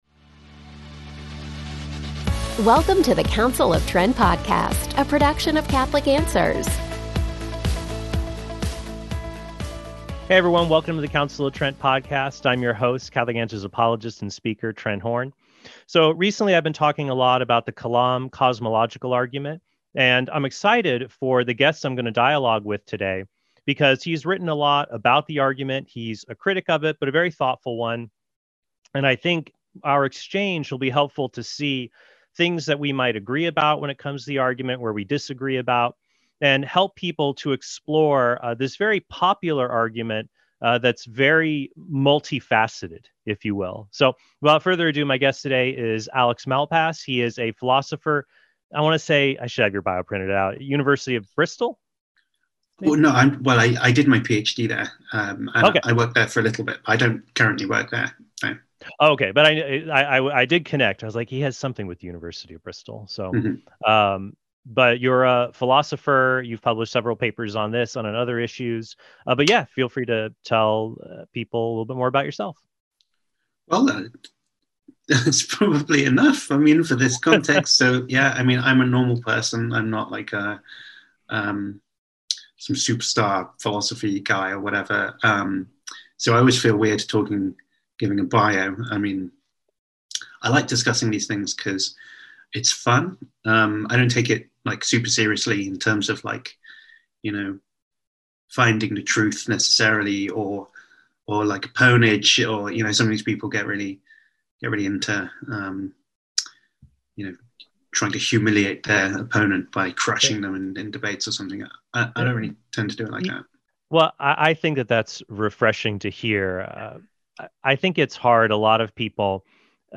DIALOGUE: Does the Kalam Argument Work?